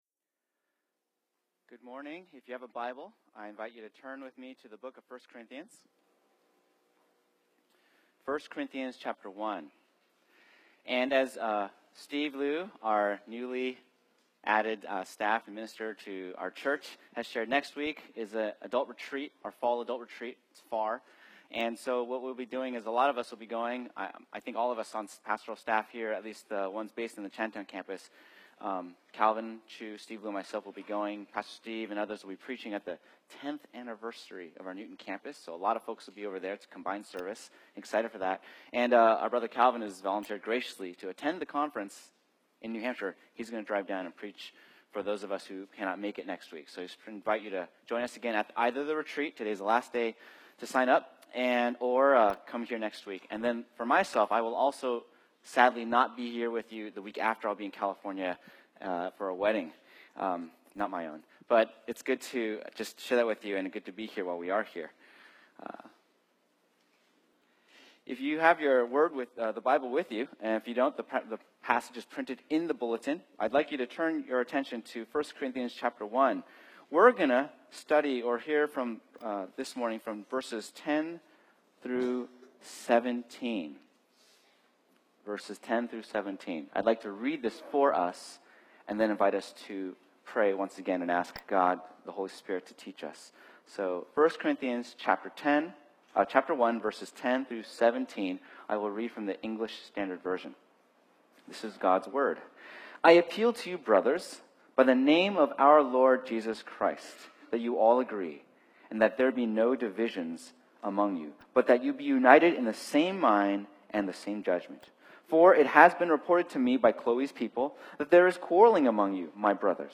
Tag: Recent Sermons - Page 176 of 178 | Boston Chinese Evangelical Church